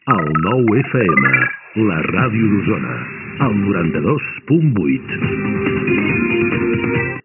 Primer indicatiu de l'emissora
Primer dia d'emissió.